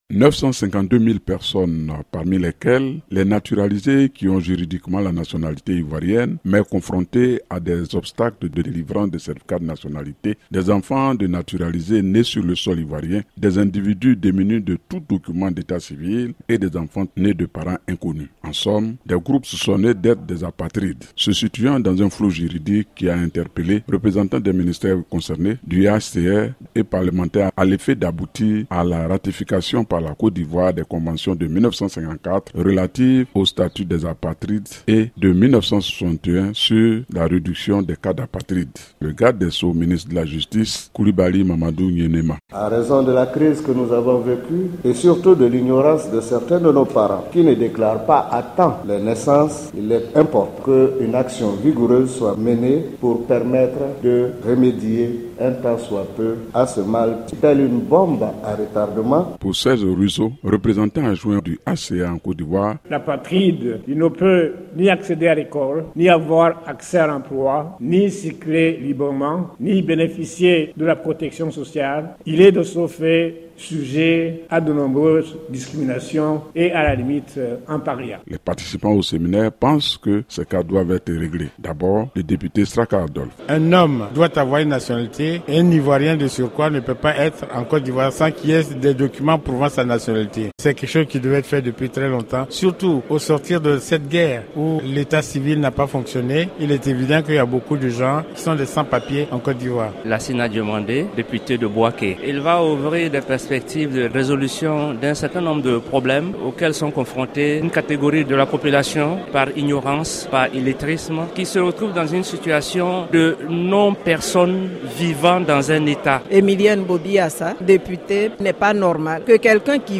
Le compte-rendu
à Abidjan